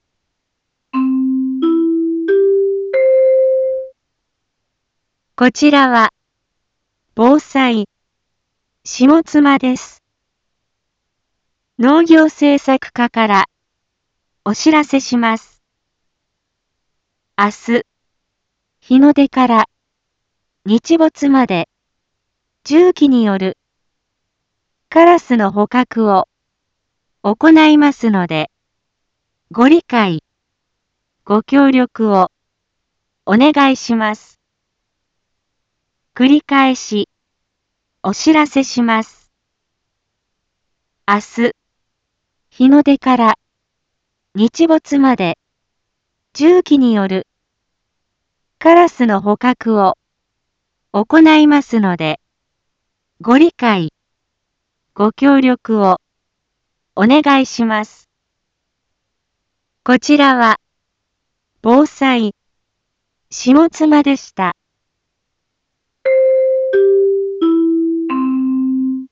一般放送情報
Back Home 一般放送情報 音声放送 再生 一般放送情報 登録日時：2026-01-31 18:01:26 タイトル：有害鳥捕獲についてのお知らせ インフォメーション：こちらは、ぼうさいしもつまです。